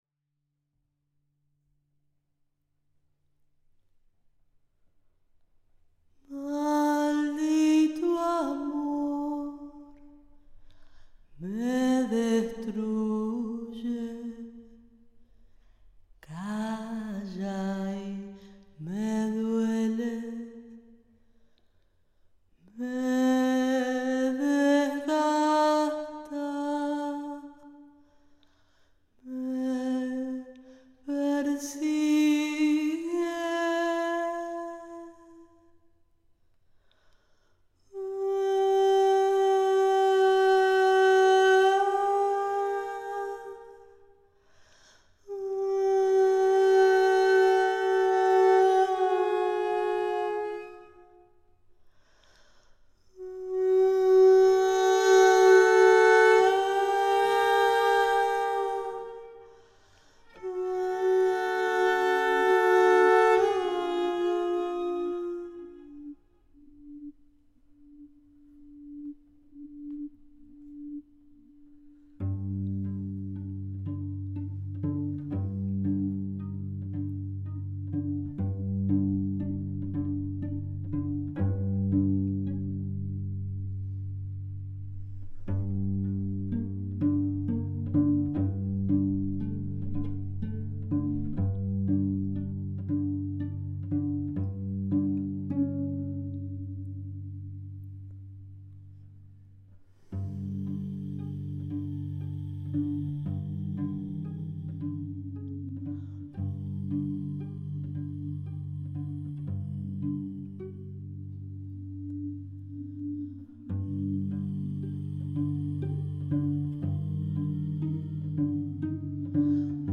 for non-lyric female voice, cello and electronics
Recorded in Buenos Aires